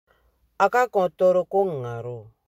Lecture et prononciation
Lisez les phrases suivantes à haute voix, puis cliquez sur l'audio pour savoir si votre prononciation est la bonne.